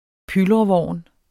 Udtale [ ˈpylʁʌˌvɒˀn ]